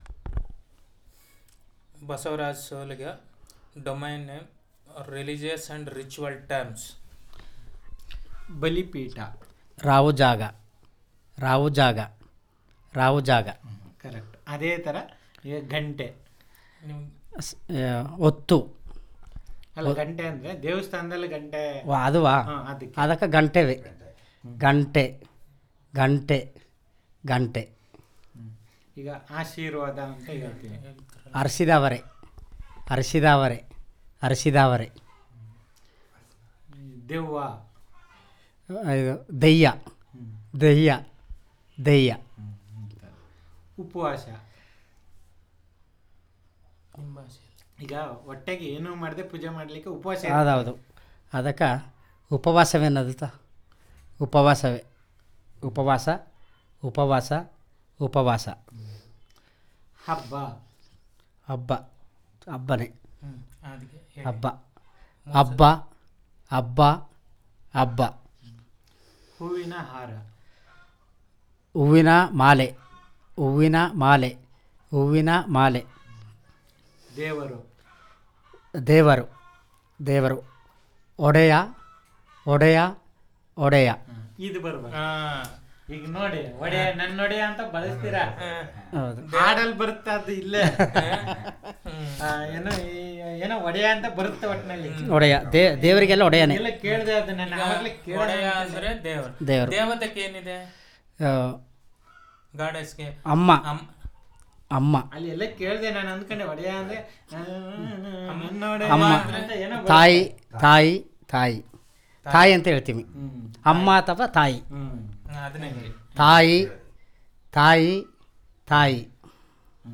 Elicitation of words about Religious and Rituals